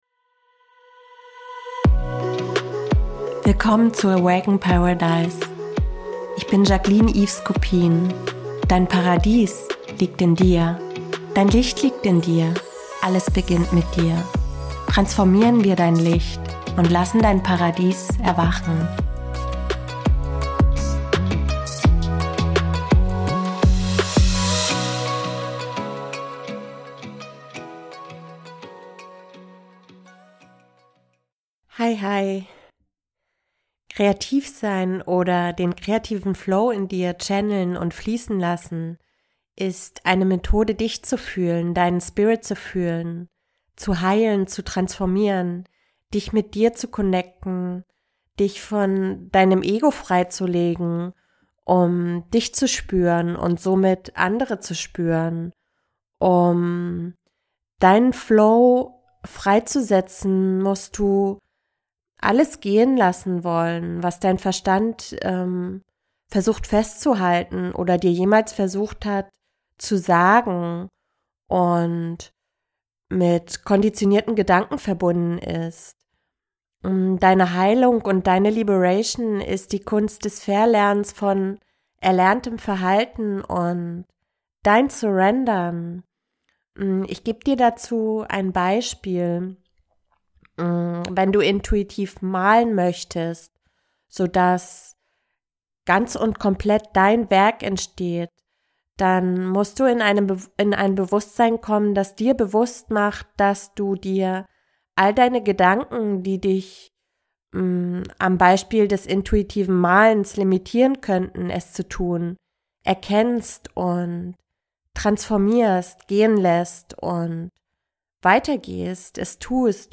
Aktiviere deine Schöpferkraft. Hier erfährst du, wie du deinen kreativen Flow channelst und damit du dich ganz hineinführen kannst, gibt es nach meinen Worten einen Beat als Tool für dich.